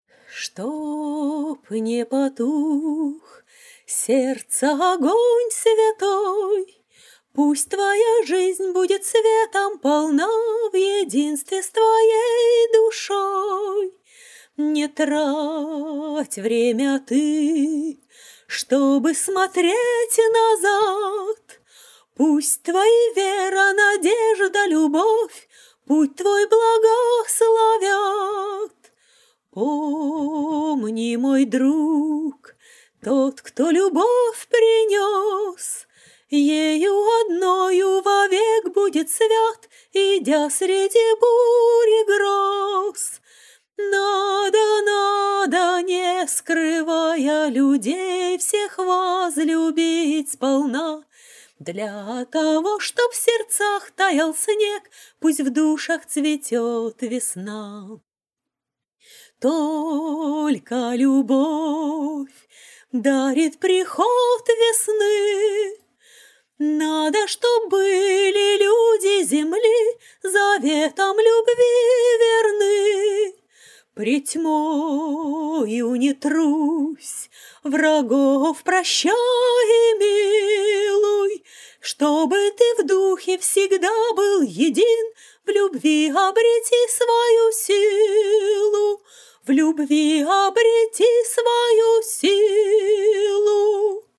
кавер-версия на мотив вальса
акапелла